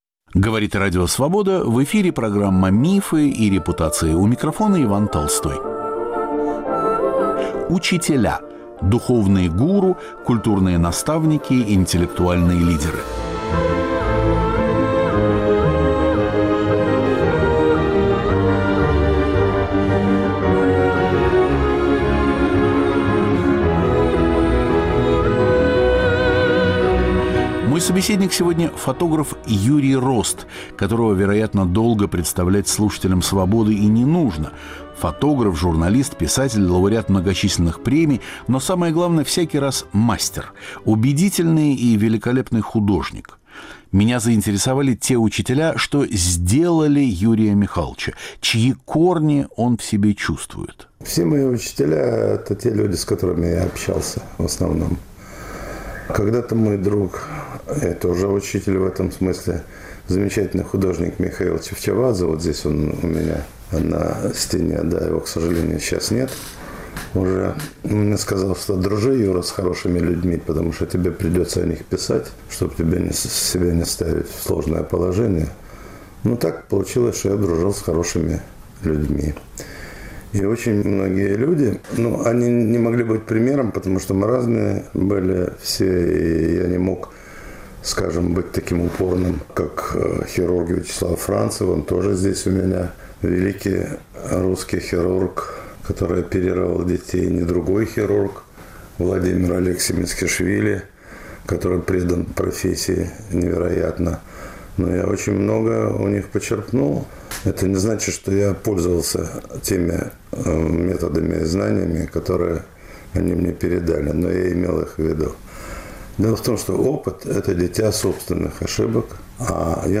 Беседа с фотографом Юрием Ростом о жизни и его учителях.